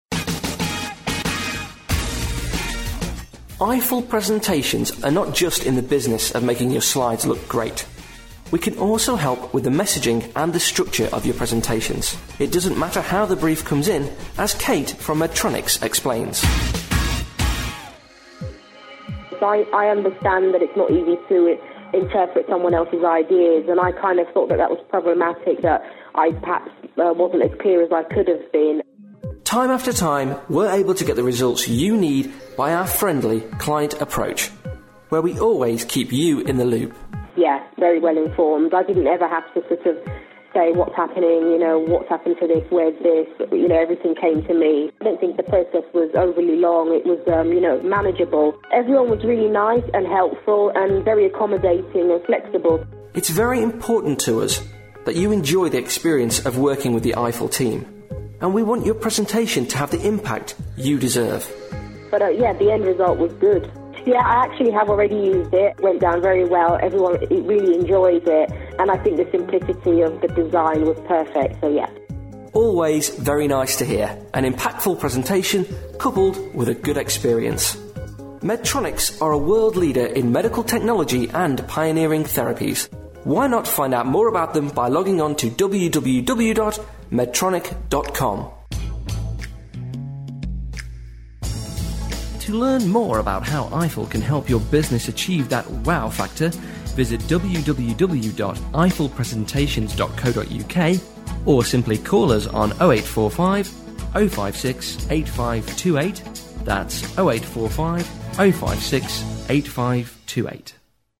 We've dusted down some of our old Podcasts and audio testimonials...and it's lovely to hear from old friends.